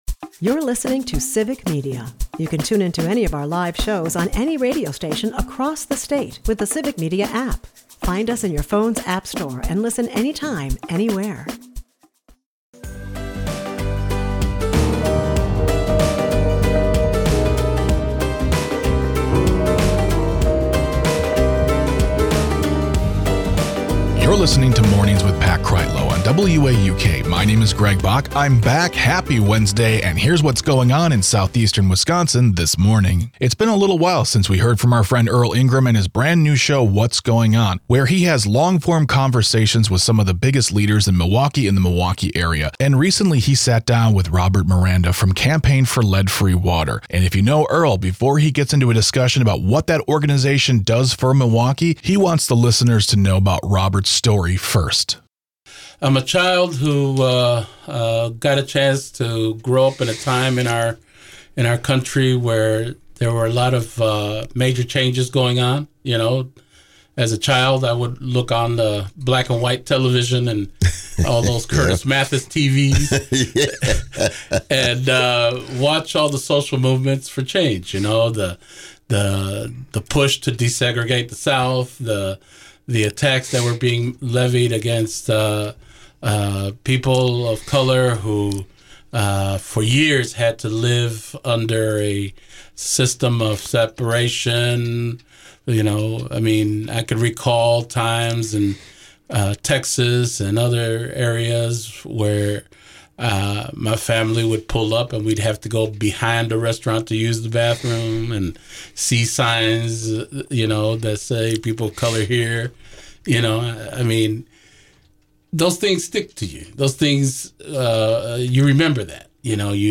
WAUK Morning Report is a part of the Civic Media radio network and air four times a morning.